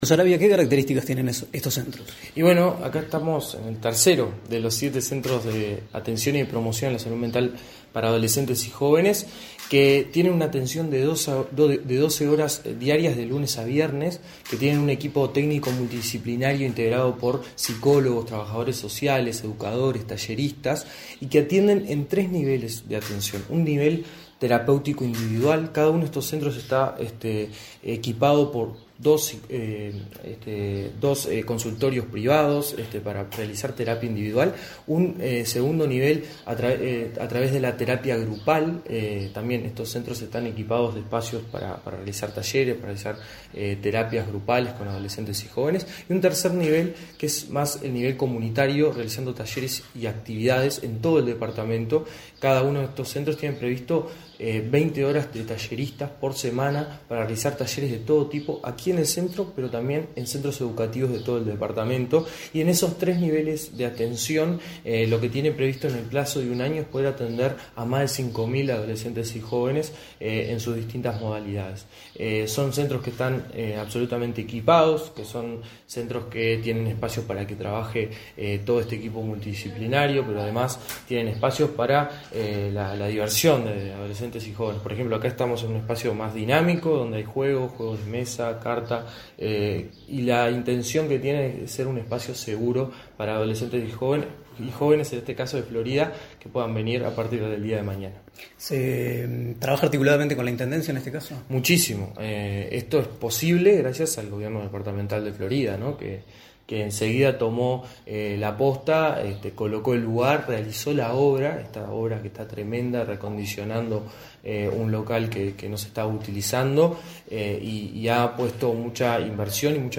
Entrevista al director del INJU, Aparicio Saravia
Entrevista al director del INJU, Aparicio Saravia 31/10/2024 Compartir Facebook X Copiar enlace WhatsApp LinkedIn En la ciudad de Florida fue inaugurado, este 31 de octubre, el centro Ni Silencio Ni Tabú, dedicado al abordaje de la salud mental en jóvenes de 14 a 24 años. En la oportunidad, el director del Instituto Nacional de la Juventud (INJU), Aparicio Saravia, realizó declaraciones a Comunicación Presidencial.